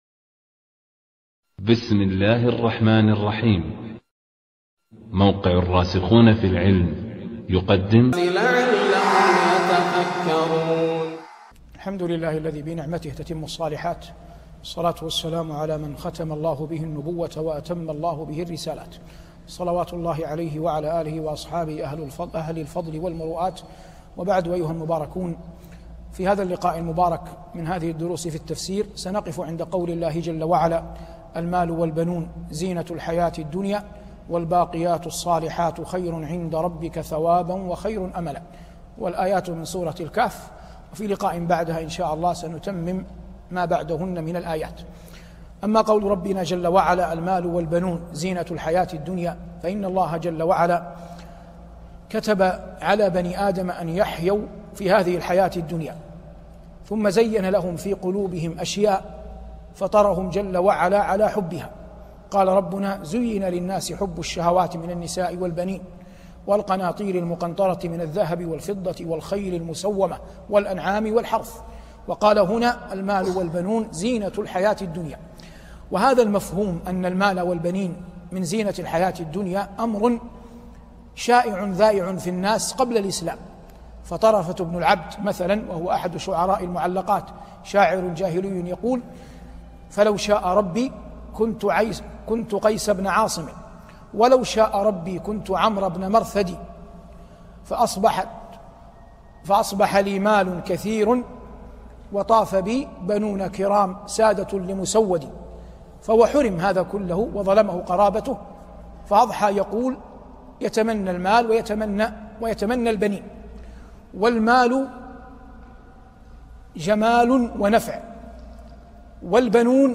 شبكة المعرفة الإسلامية | الدروس | تفسير الآية 46 سورة الكهف |صالح بن عواد المغامسي